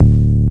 cch_bass_one_shot_rover_D.wav